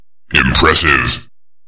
Quake_3_Impressive_Sound_Effect.mp3